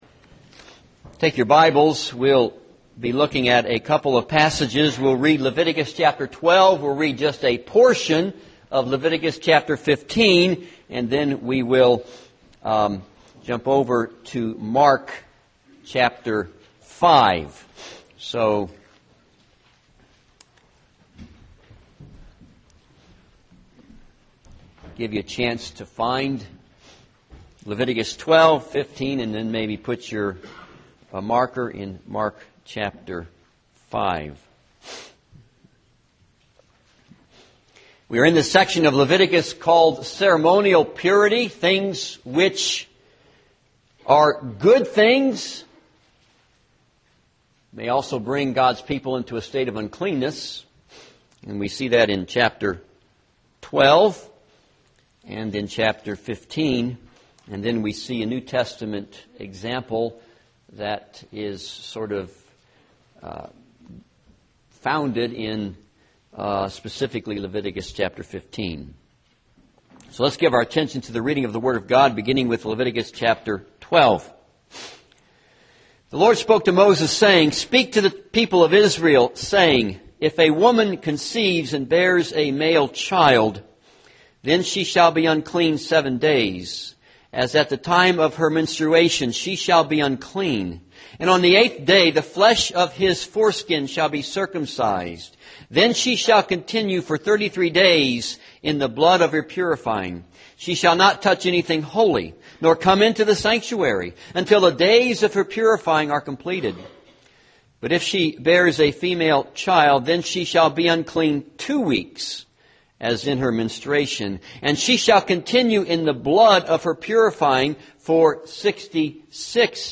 This sermon is based on Leviticus 12; Leviticus 15 and Mark 5:21-34.